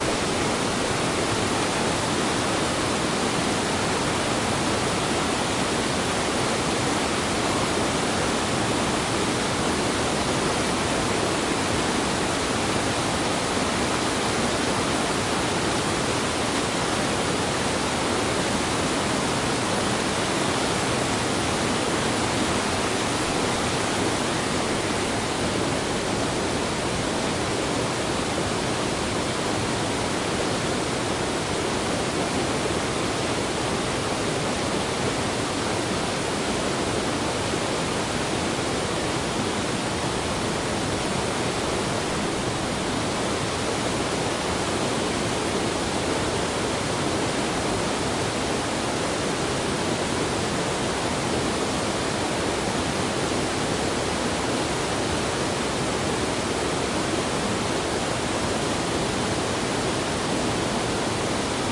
Parrots
描述：Sounds of summer. The Parrots raid the 'Umbrella" tree outside my house. Their cacophony is distracting and exciting. Someone, a few houses over, is mowing their lawn, a fortnightly necessity during the tropical wet, providing another iconic sound to the urban summer.
标签： Queensland tropics Parrots Australia Lawnmower Atherton Summer
声道立体声